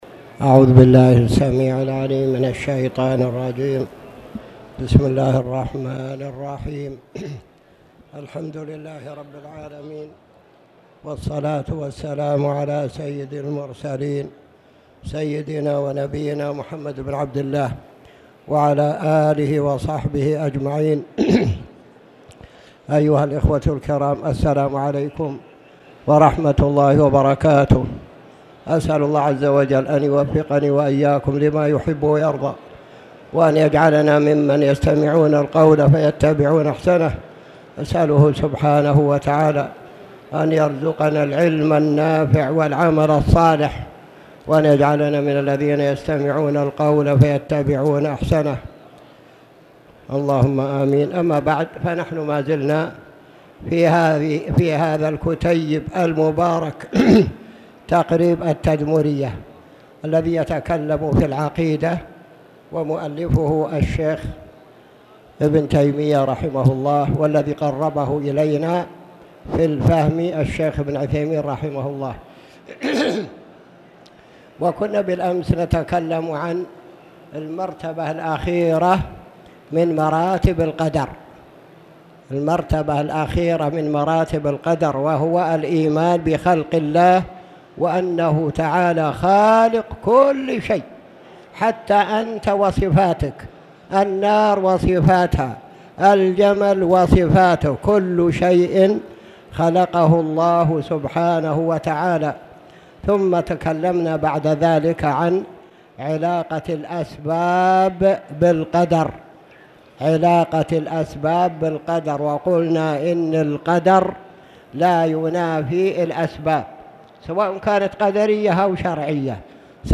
تاريخ النشر ١٤ صفر ١٤٣٨ هـ المكان: المسجد الحرام الشيخ